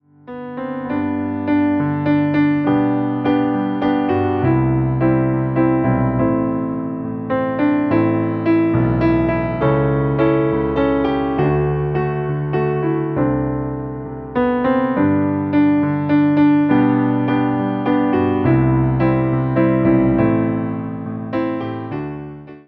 Wersja demonstracyjna:
68 BPM
G – dur